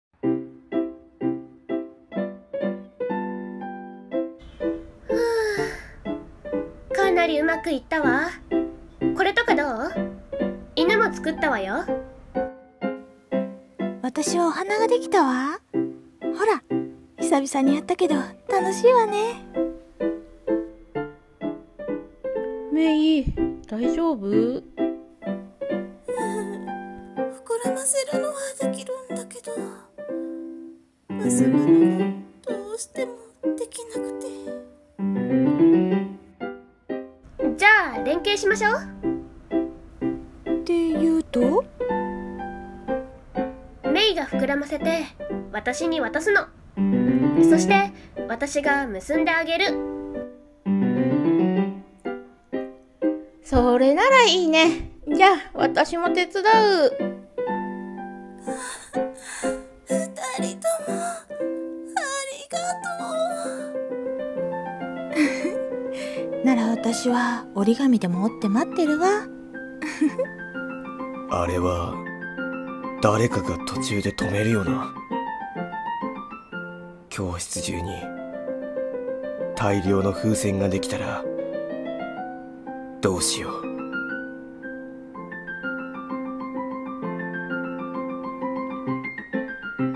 楽しいBGM